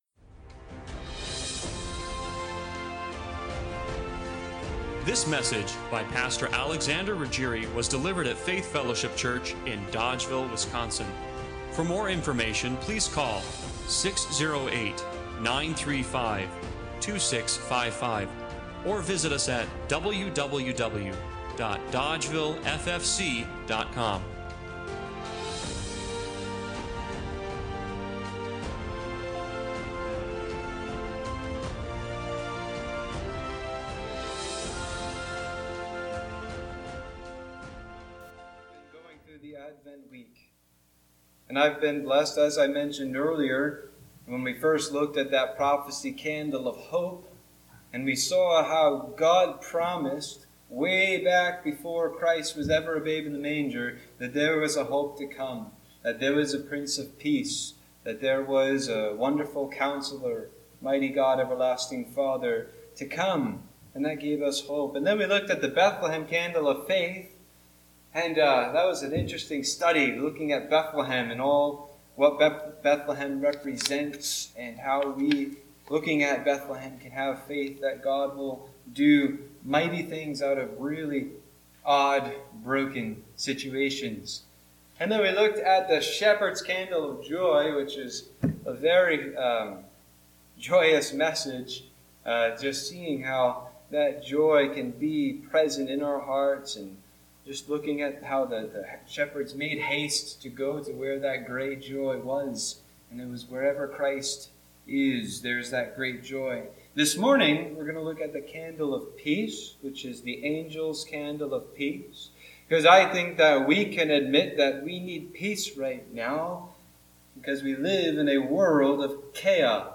Luke 2:14 Service Type: Sunday Morning Worship Bible Text